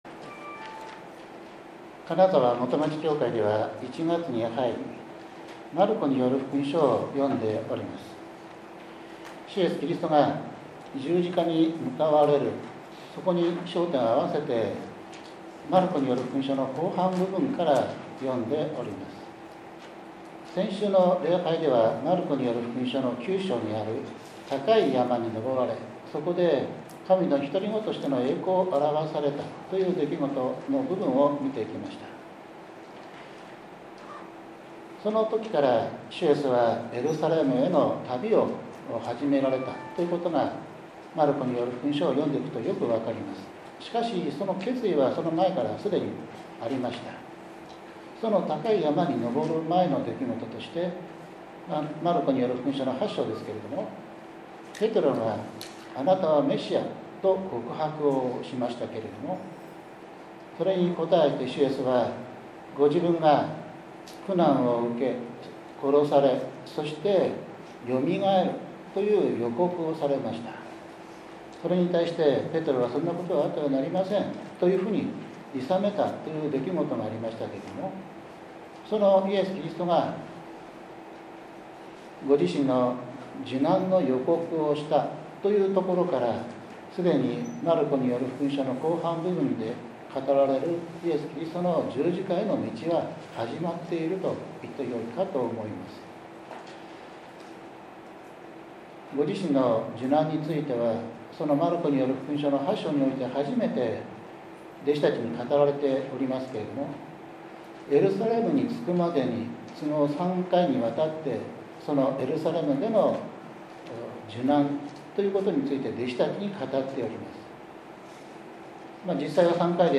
１月１９日（日）主日礼拝